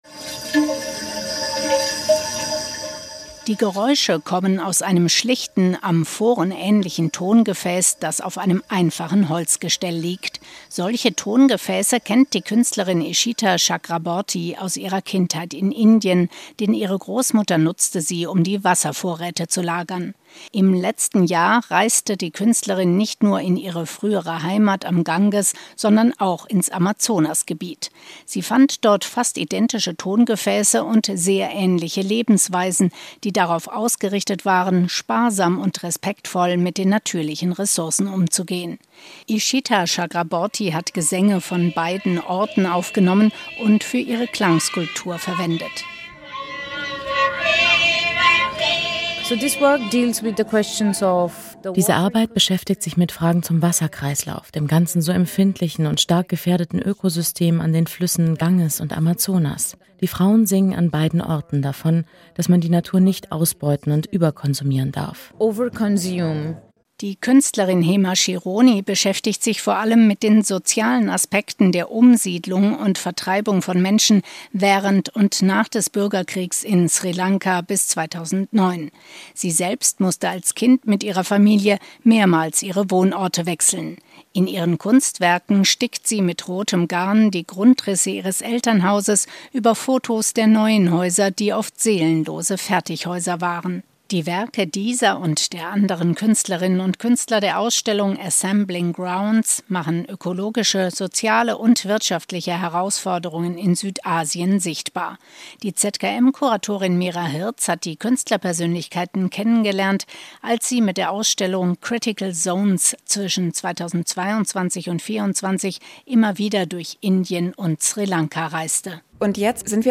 Die Geräusche kommen aus einem schlichten, Amphoren-ähnlichen Tongefäß, das auf einem einfachen Holzgestell liegt.